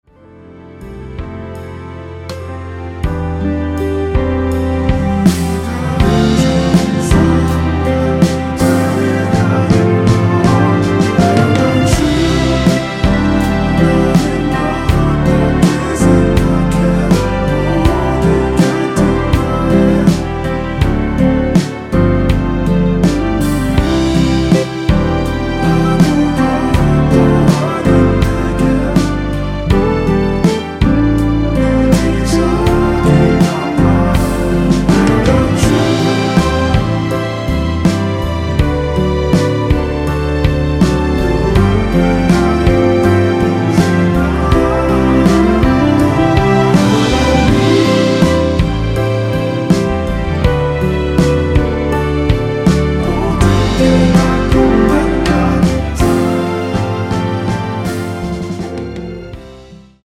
원키에서(-2)내린 코러스 포함된 MR입니다.(미리듣기 확인)
◈ 곡명 옆 (-1)은 반음 내림, (+1)은 반음 올림 입니다.
앞부분30초, 뒷부분30초씩 편집해서 올려 드리고 있습니다.